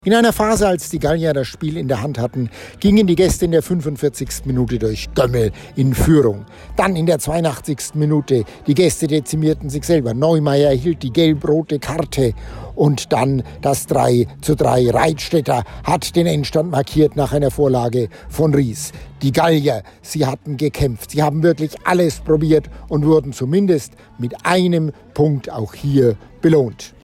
Der TSV Großbardorf und die DJK Ammerthal haben sich mit einem 3 zu 3 in der Bioenergie-Arena getrennt. Sportreporter